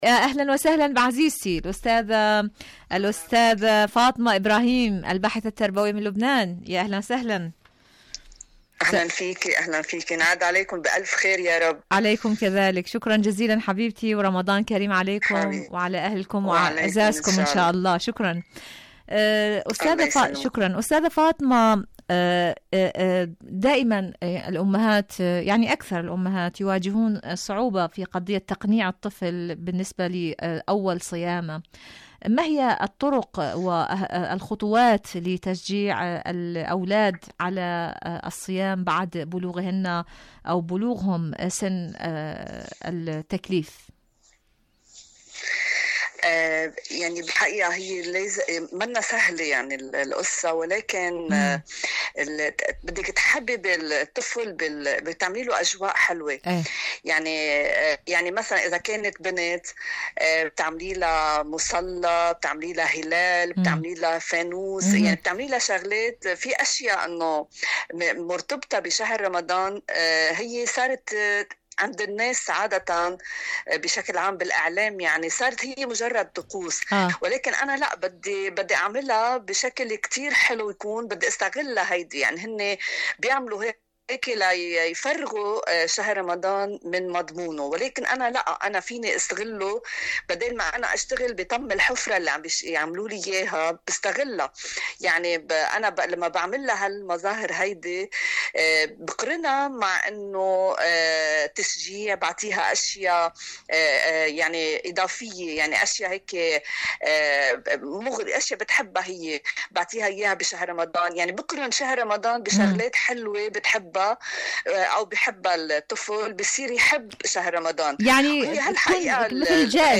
مقابلات برنامج عالم المرأة الوصايا المرأة الصبي بالصيام مقابلات إذاعية كيف أساعد أبنائي على صومهم؟ تعويد أبنائنا على الصيام صيام الأطفال برامج إذاعة طهران العربية صيام الأبناء الصبي والصوم شاركوا هذا الخبر مع أصدقائكم ذات صلة آليات إيران للتعامل مع الوكالة الدولية للطاقة الذرية..